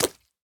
Minecraft Version Minecraft Version 1.21.5 Latest Release | Latest Snapshot 1.21.5 / assets / minecraft / sounds / mob / frog / long_jump2.ogg Compare With Compare With Latest Release | Latest Snapshot
long_jump2.ogg